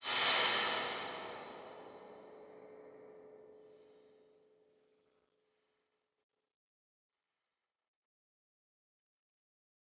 X_BasicBells-C6-mf.wav